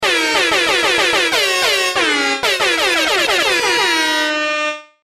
Play, download and share tribute airhorn original sound button!!!!
tribute-airhorn.mp3